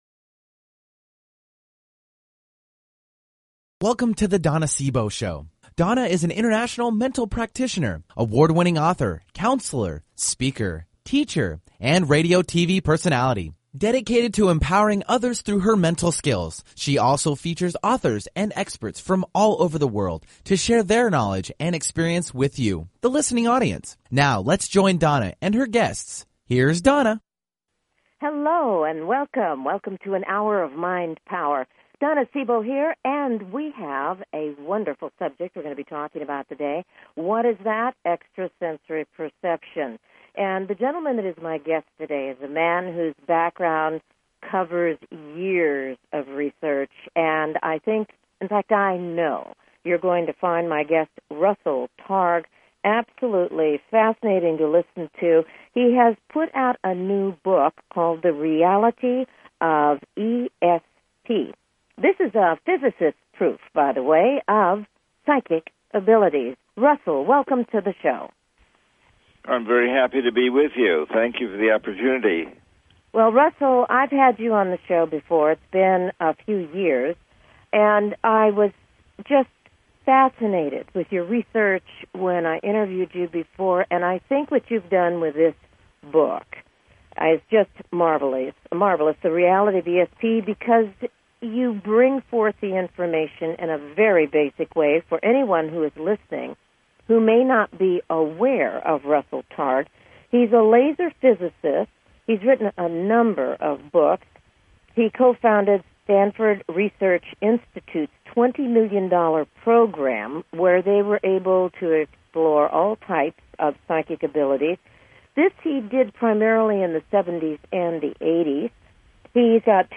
Guest, Russell Targ